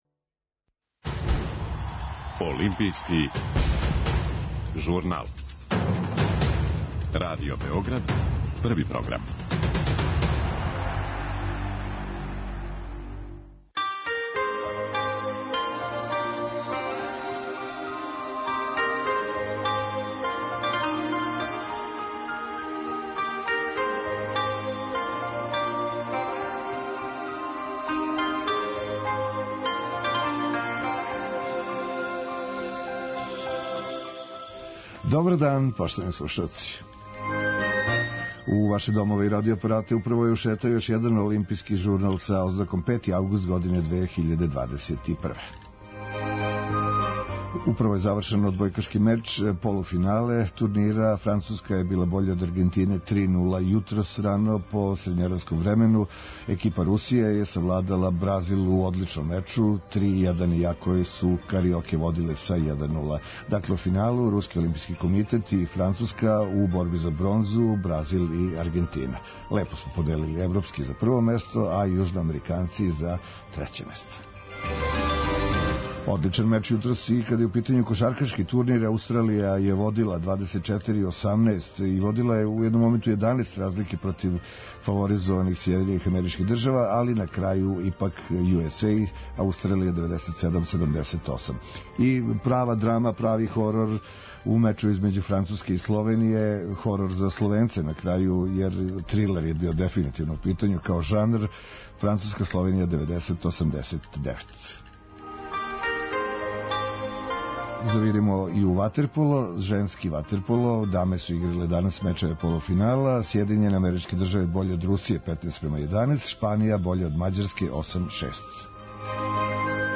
У Олимпијском журналу можете слушати најновије извештаја наших репортера из Токија.